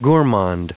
Prononciation du mot gourmand en anglais (fichier audio)